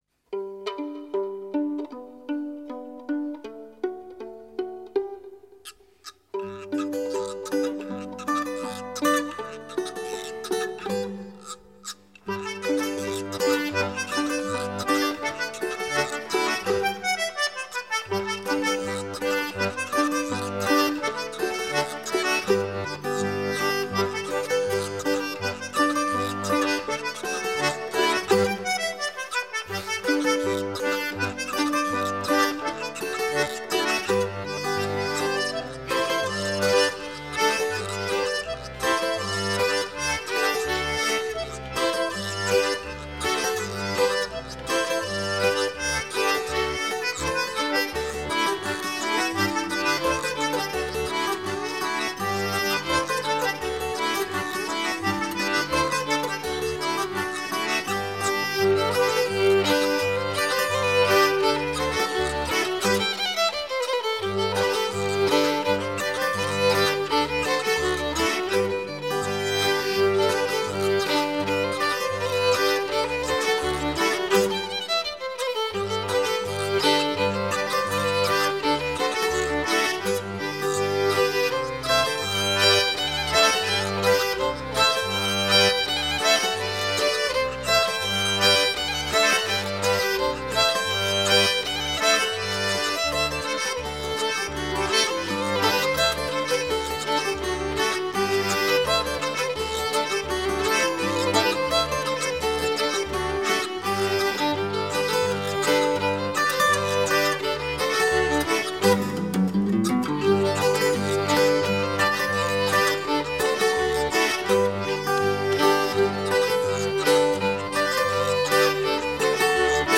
Musiques à danser
accordéon diatonique, percus
guitare, bouzouki